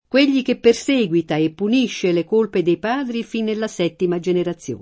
perseguitare v.; perseguito [perS%gUito] — es. con acc. scr.: quegli che perséguita e punisce le colpe dei padri fin nella settima generazione [
kU%l’l’i ke pperS%gUita e ppun&šše le k1lpe dei p#dri fin nella S$ttima JeneraZZL1ne] (Carducci)